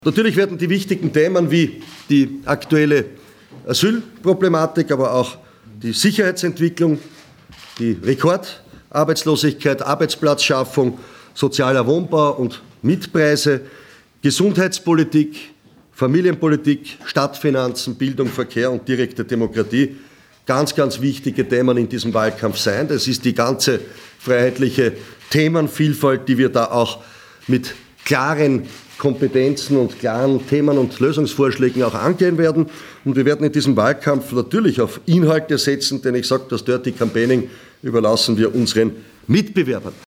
O-Töne von HC Strache